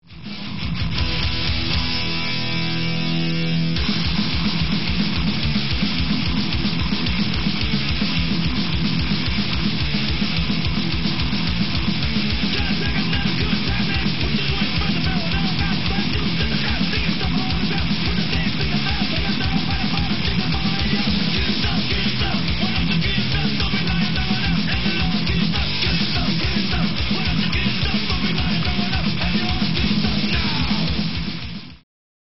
cranking it live